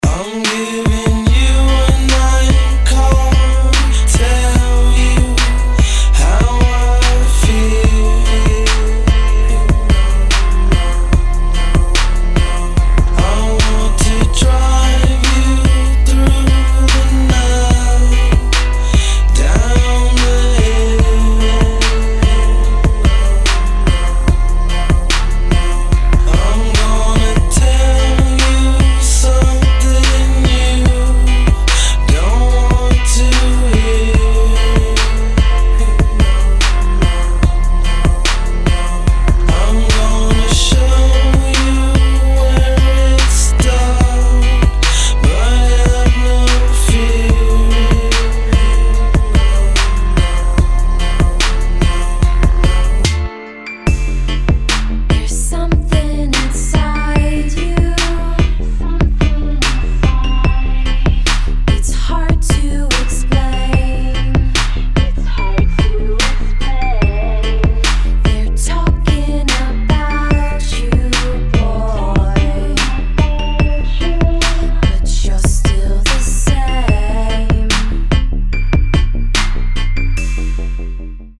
• Качество: 320, Stereo
Хип-хоп
Trap
медленные
кавер
в стиле Trap / Hip-hop